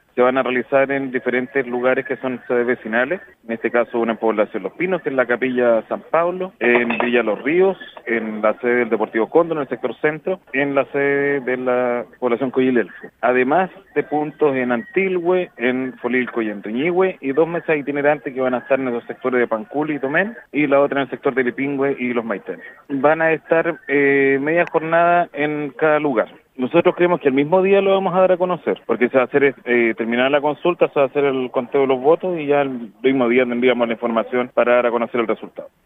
alcalde-los-lagos-ok.mp3